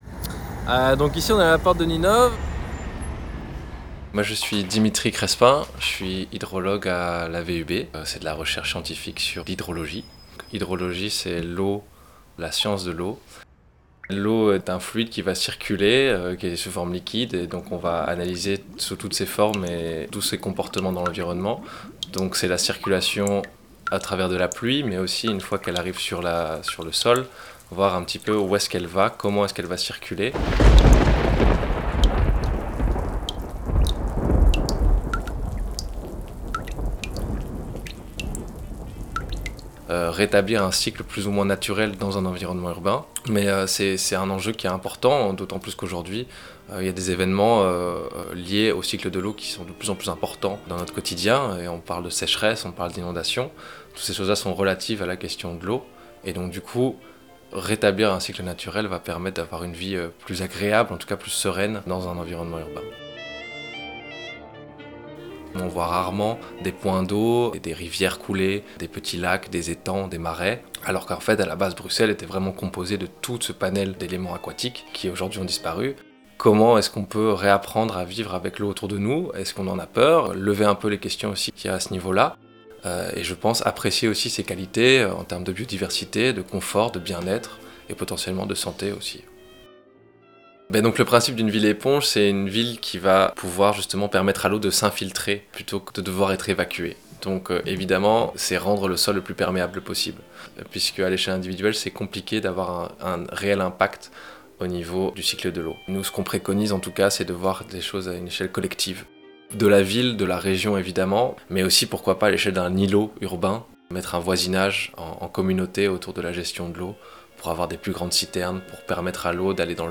Vous écoutez un podcast réalisé par La Fonderie et le Musée des Égouts, dans le cadre d’une balade sonore en autonomie. Cette balade sonore est un voyage dans les coulisses des métiers de l’eau à Bruxelles.